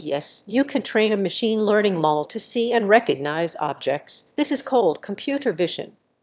role1_VacuumCleaner_1.wav